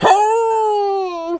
pgs/Assets/Audio/Animal_Impersonations/wolf_hurt_01.wav at master
wolf_hurt_01.wav